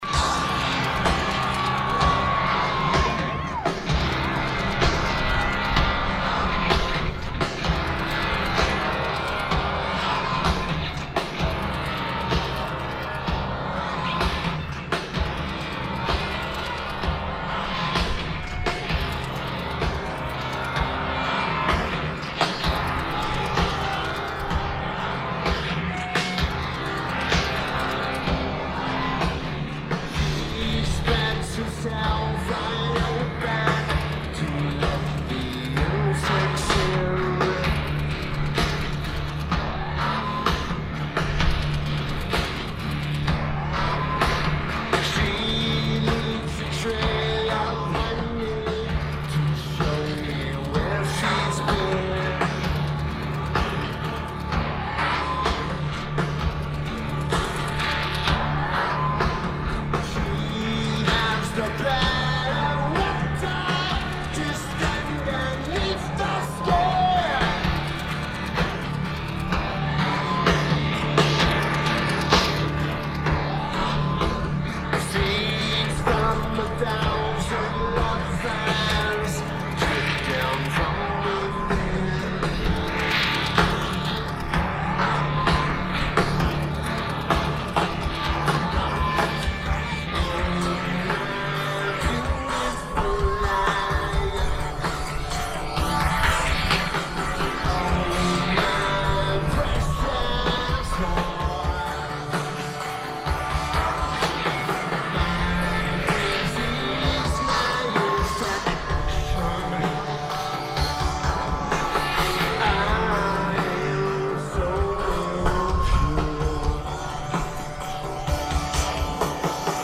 Red Rocks Amphitheatre
Lineage: Audio - AUD (ATu853s + AT8532s + Edirol R09)